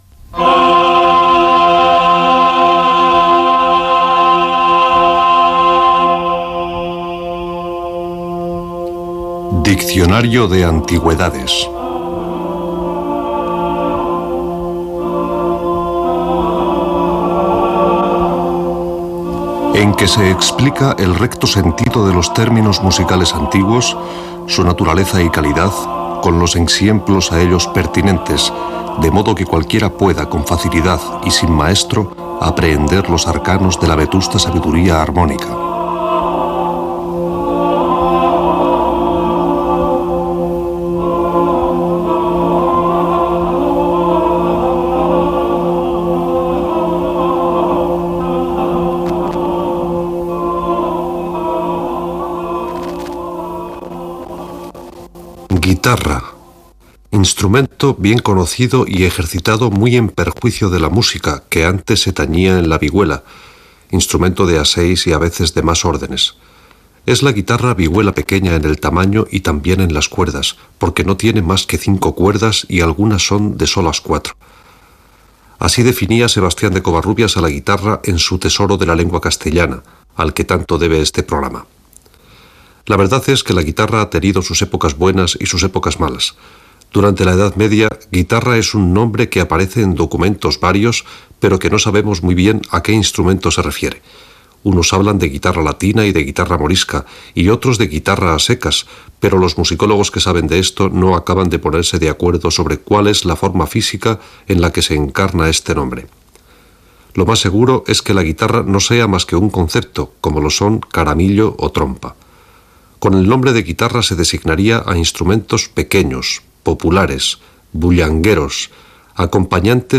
Careta d'entrada del programa, espai dedicat a la guitarra.
FM